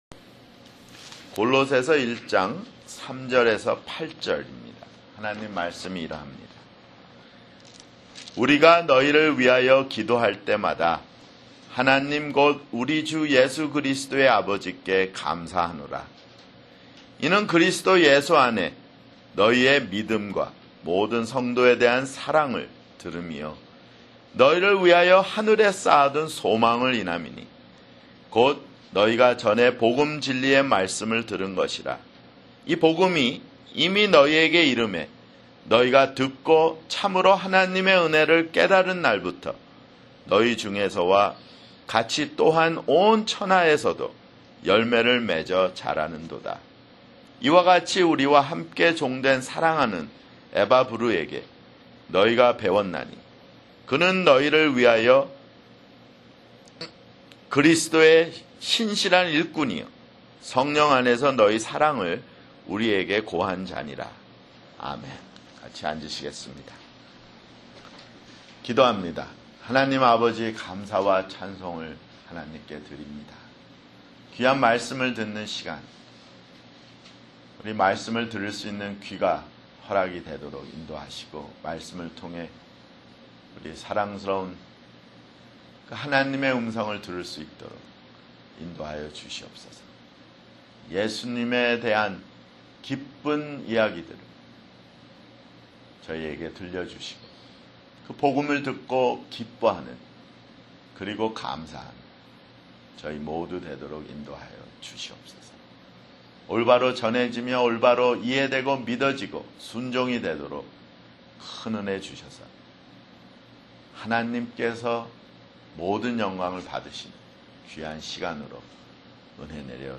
[주일설교] 골로새서 (9)